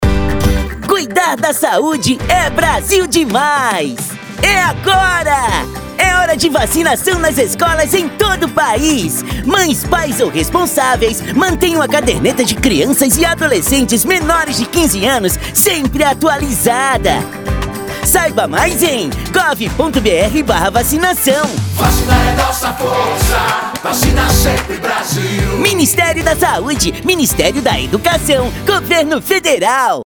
Áudio - Spot 30seg - Vacinação nas Escolas - 1,15mb .mp3 — Ministério da Saúde